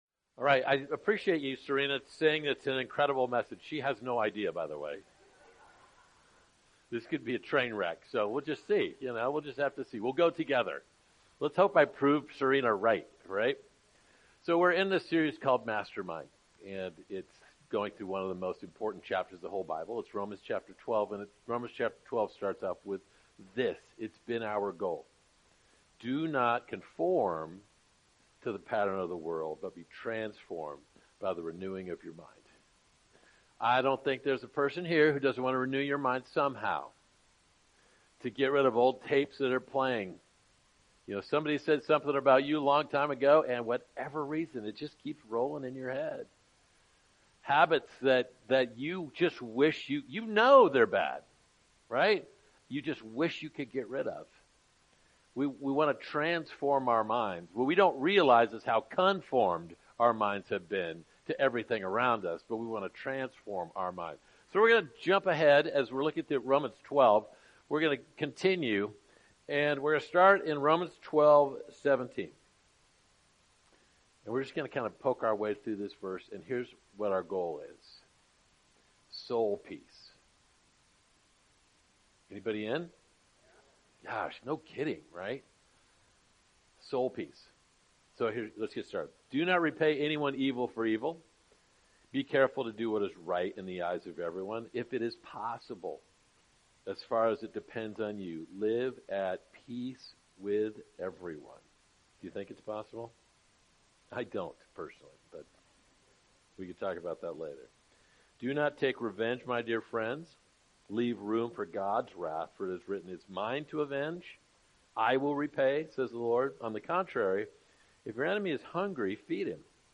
Sermons | Covenant Grove Church